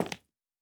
Tile_Mono_05.wav